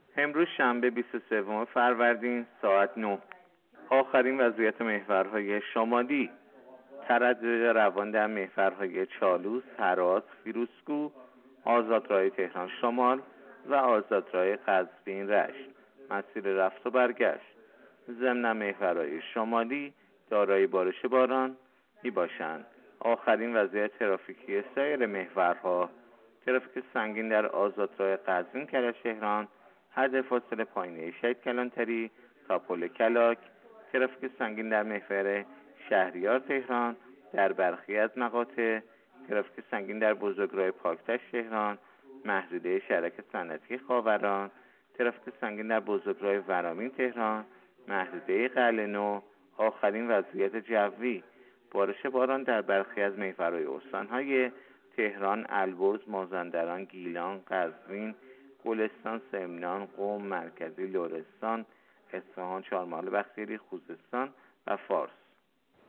گزارش رادیو اینترنتی از آخرین وضعیت ترافیکی جاده‌ها ساعت ۹ بیست و سوم فروردین؛